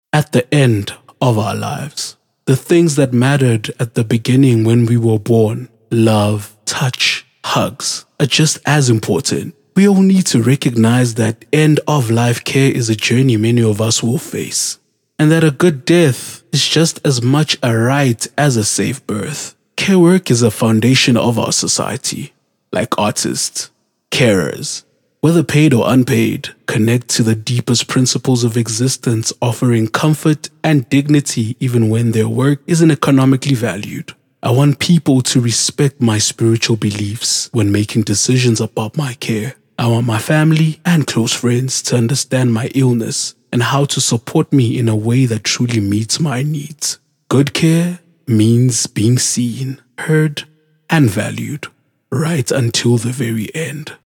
confident, energetic
My deep, captivating voice has become my signature, allowing me to engage audiences across various platforms, from commercials to online campaigns.
My demo reels